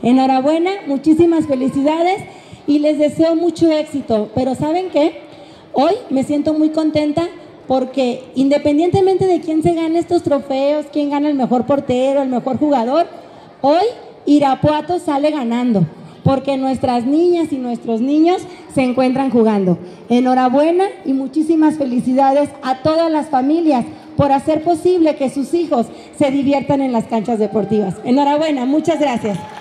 Directora general de CODE Guanajuato, Yendi Cortinas López